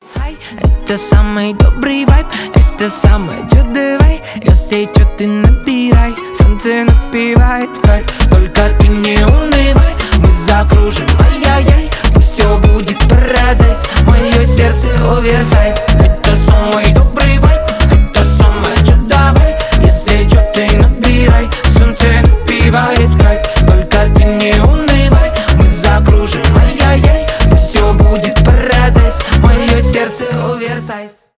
позитивные
поп